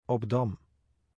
Obdam (Dutch pronunciation: [ɔbˈdɑm]